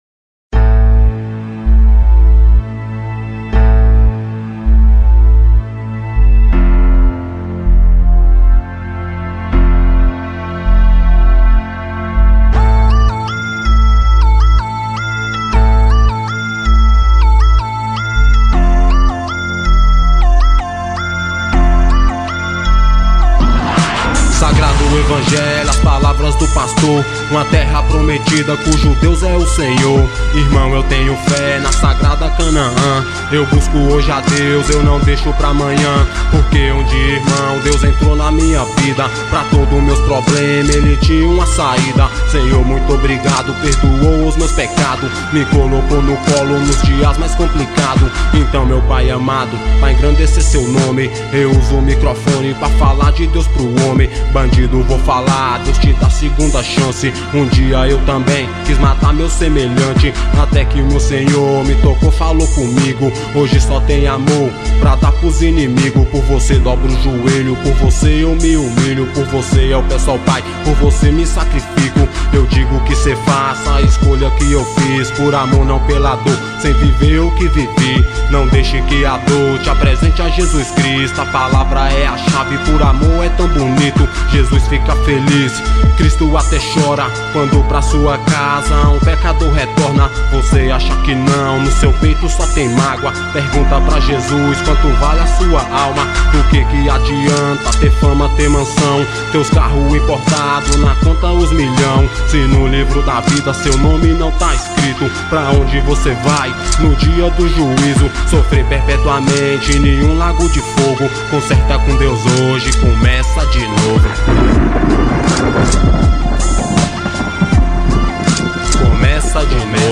Rap.